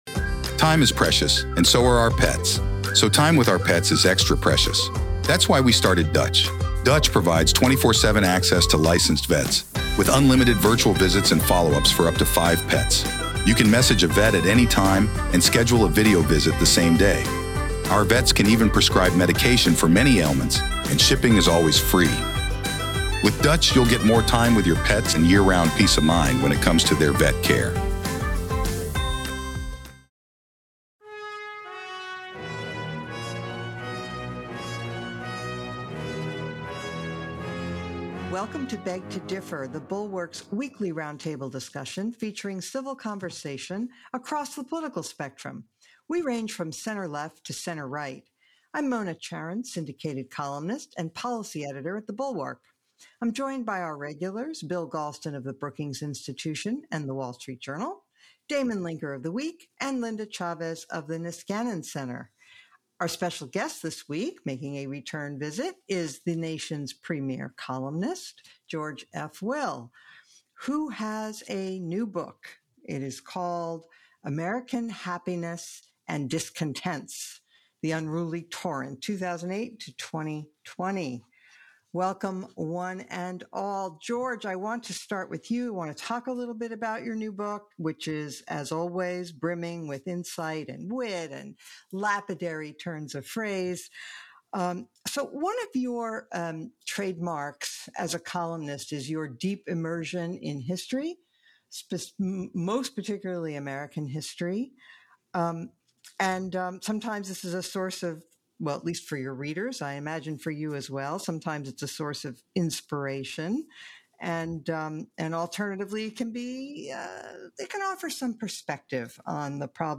George F. Will joins B2D panel to discuss his new book, and also whether Facebook should be regulated, and the Democrats' hopes for social welfare.